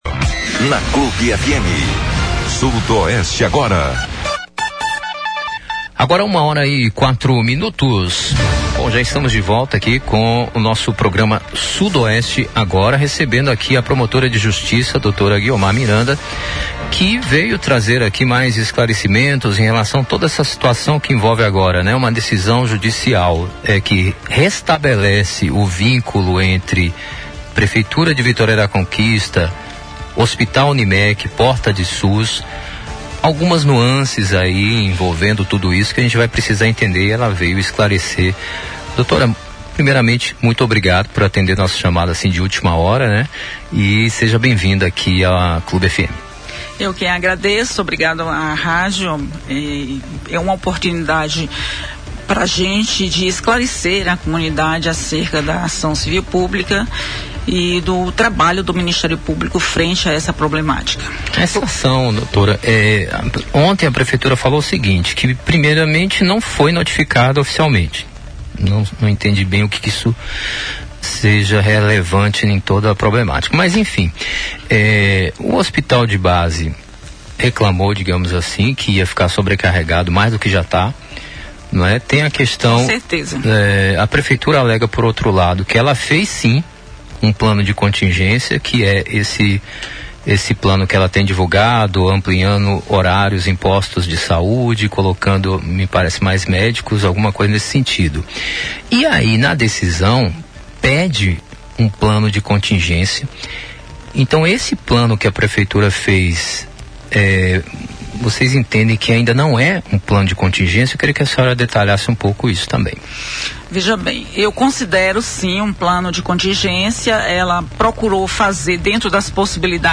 A promotora de Justiça Guiomar Miranda de Oliveira Melo detalhou, em entrevista à Rádio Clube de Conquista nesta quarta-feira (4), a liminar que obriga o Hospital UNIMEC [Unidade Médico Cirúrgica Limitada] a retomar imediatamente o atendimento pelo Sistema Único de Saúde (SUS) por 180 dias. A medida judicial atende a uma Ação Civil Pública do Ministério Público e visa impedir o colapso da rede hospitalar após o encerramento unilateral do contrato.